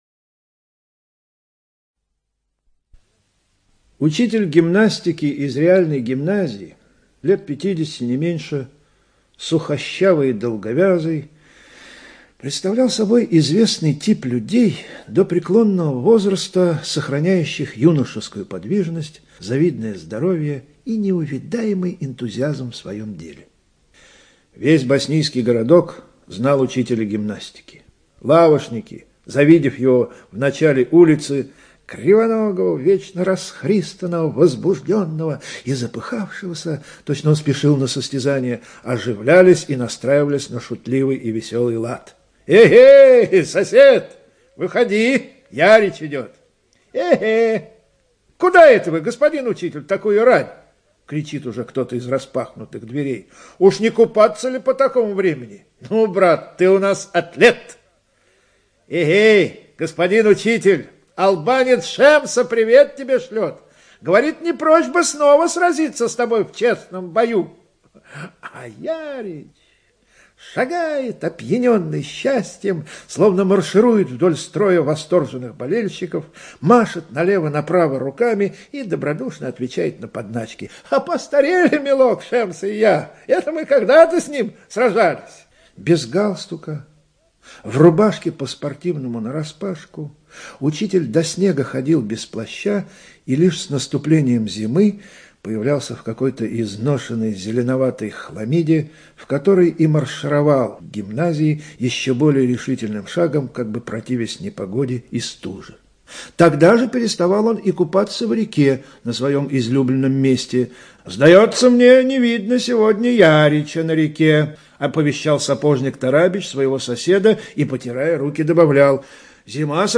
ЧитаетЭтуш В.